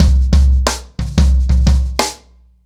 Wireless-90BPM.39.wav